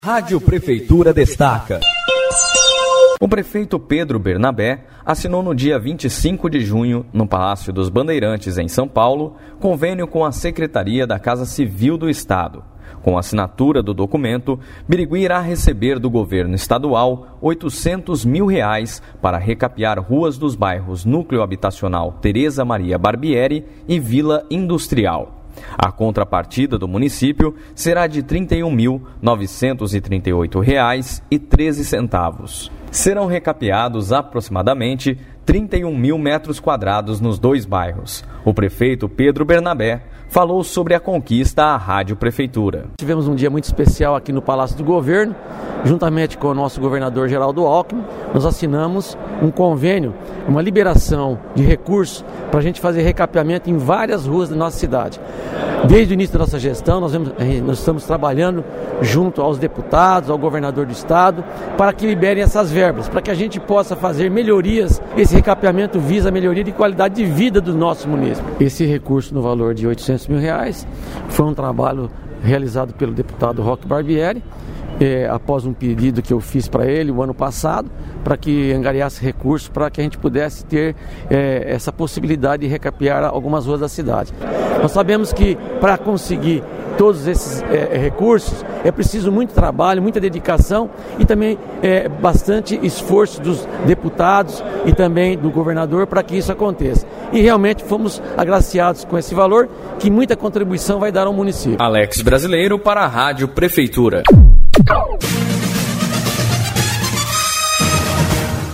O prefeito Pedro Bernabé falou sobre a assinatura do convênio à Rádio Prefeitura.